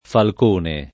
vai all'elenco alfabetico delle voci ingrandisci il carattere 100% rimpicciolisci il carattere stampa invia tramite posta elettronica codividi su Facebook falcone [ falk 1 ne ] s. m. — sim. il pers. m. Falcone , i cogn.